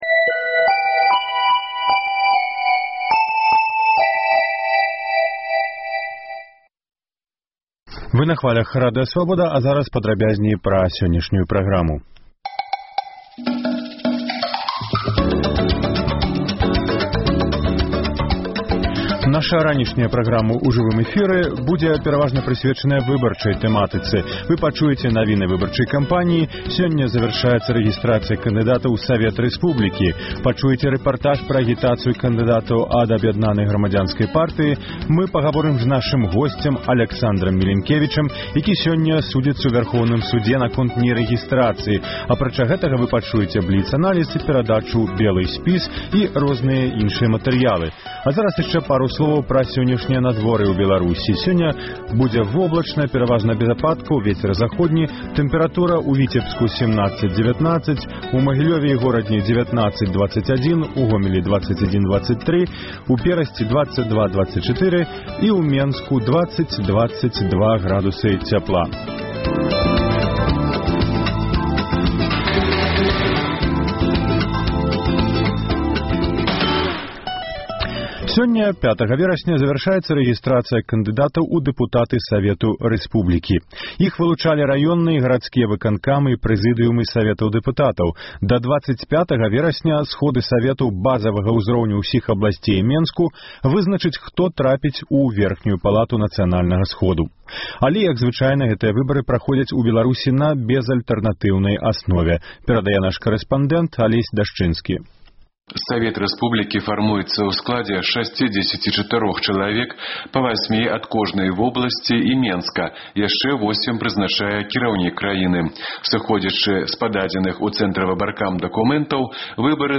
Інфармацыйны блёк: навіны Беларусі і сьвету. Паведамленьні нашых карэспандэнтаў, званкі слухачоў, апытаньні ў гарадах і мястэчках Беларусі.